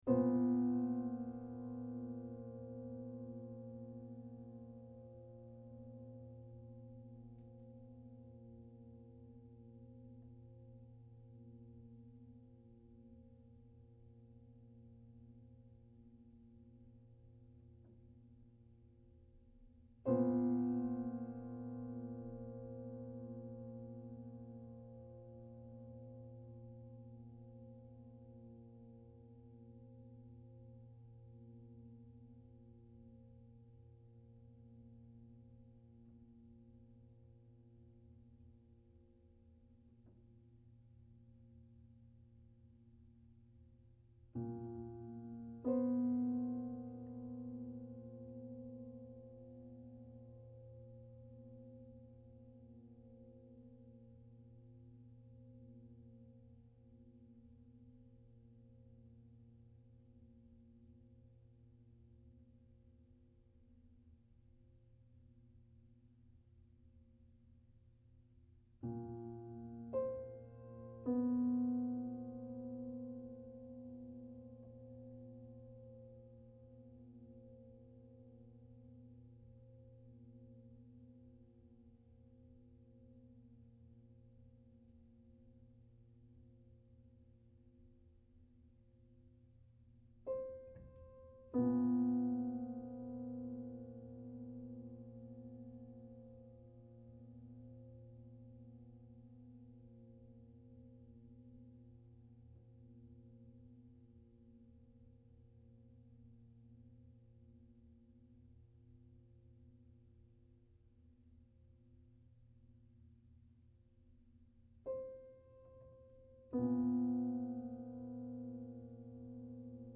International Publisher and label for New experimental Music
piano